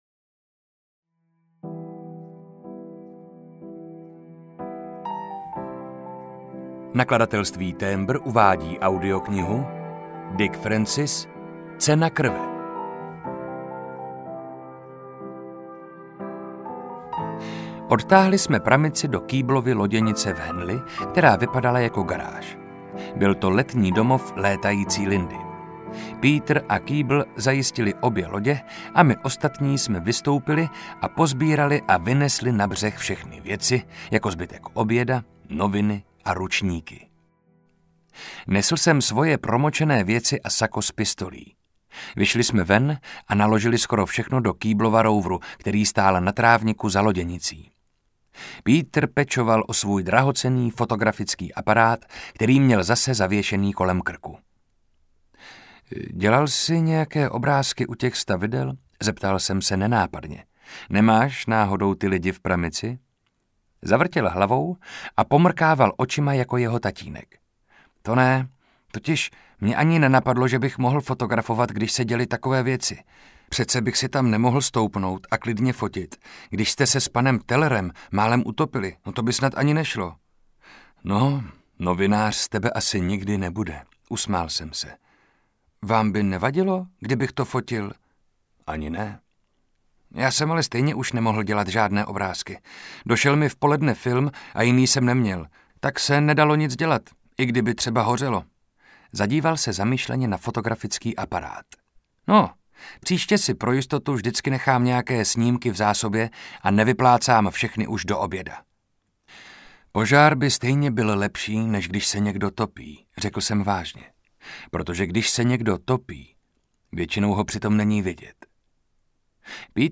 Cena krve audiokniha
Ukázka z knihy
• InterpretMarek Holý